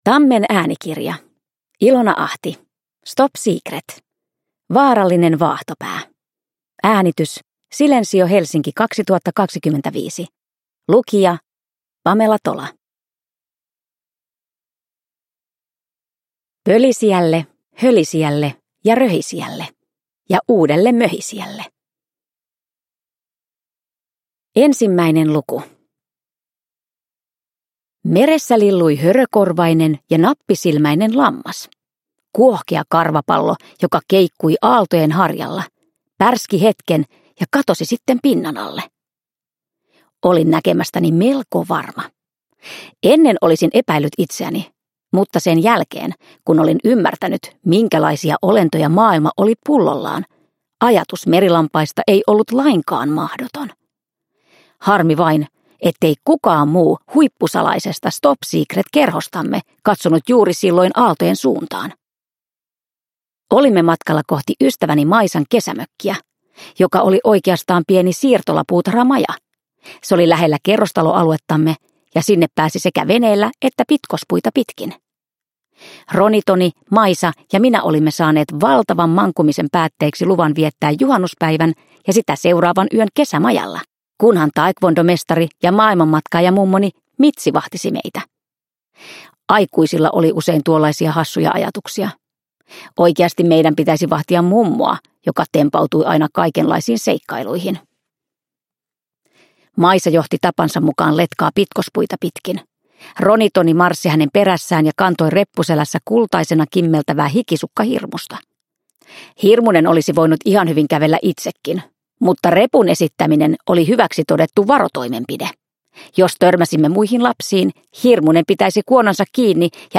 Stop Secret. Vaarallinen vaahtopää – Ljudbok
Uppläsare: Pamela Tola